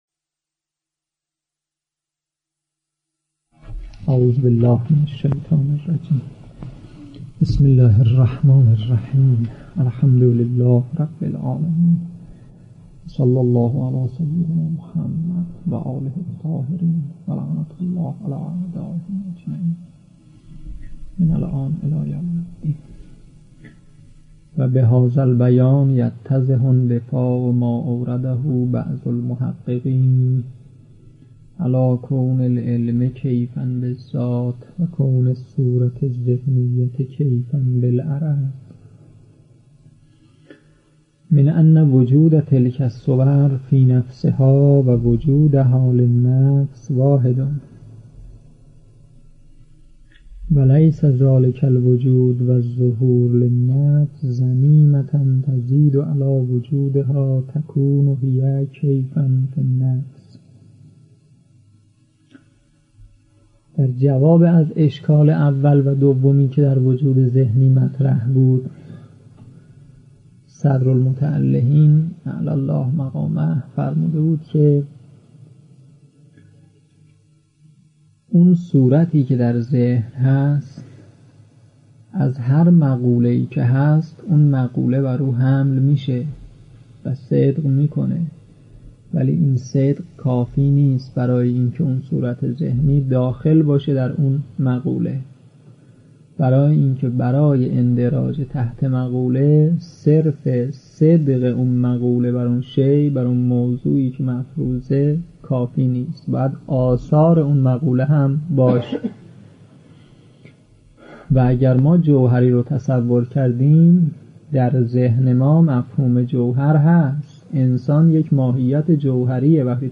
در این بخش، کتاب «بدایة الحکمة» که می‌توان دومین کتاب در مرحلۀ شناخت علم فلسفه دانست، به صورت ترتیب مباحث کتاب، تدریس می‌شود. صوت‌های تدریس متعلق به آیت الله شیخ غلامرضا فیاضی است.